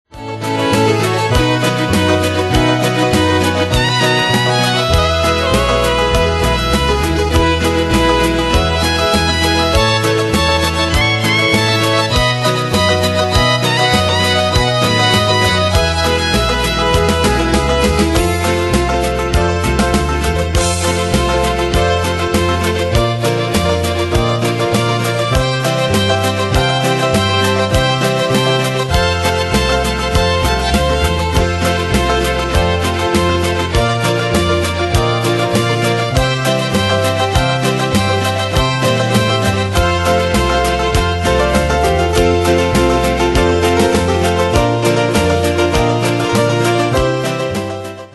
Demos Midi Audio
Style: Country Année/Year: 2000 Tempo: 100 Durée/Time: 3.10